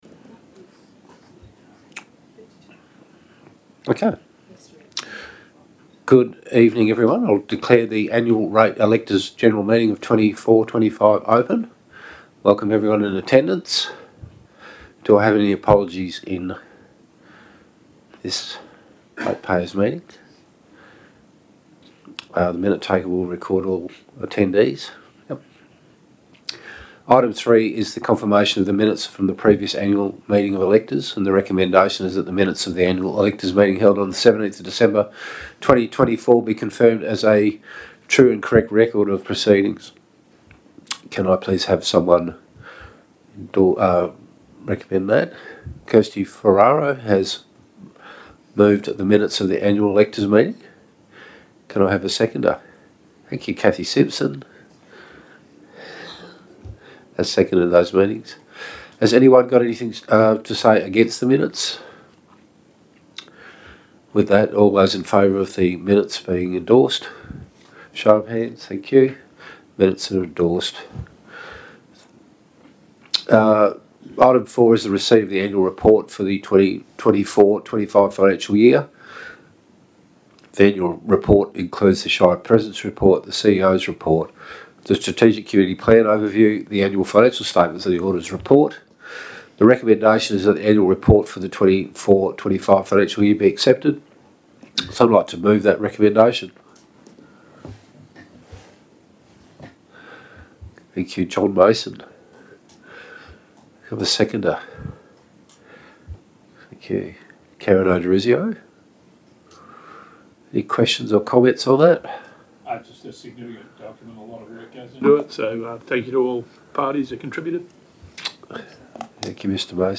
audio-recording-annual-general-meeting-of-electors-december-2025.mp3